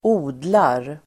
Uttal: [²'o:dlar]